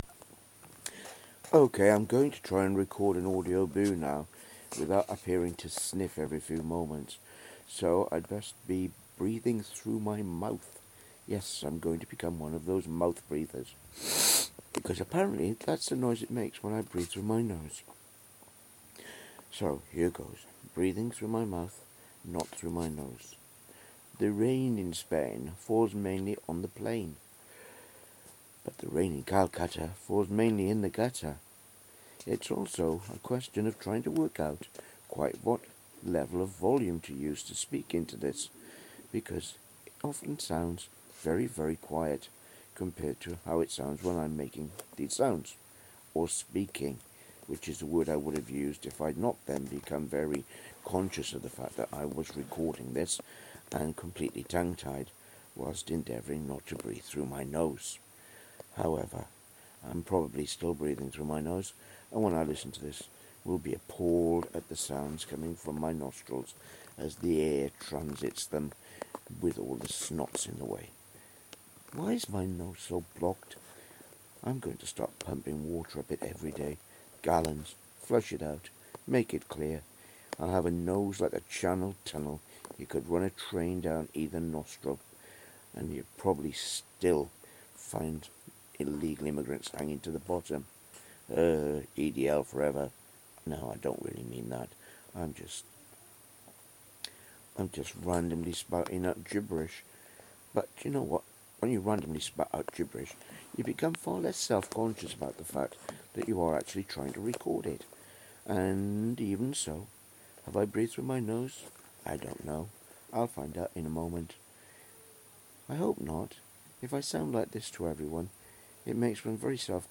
nose noises